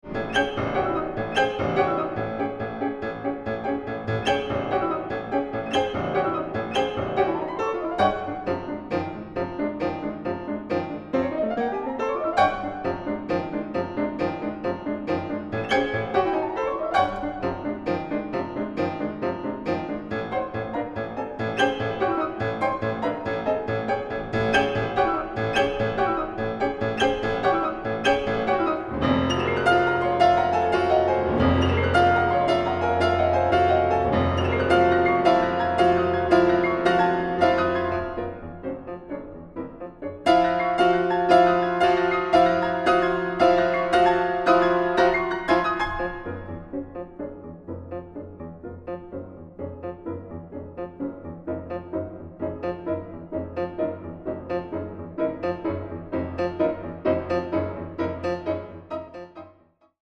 Piano
Recording: Mendelssohnsaal, Gewandhaus Leipzig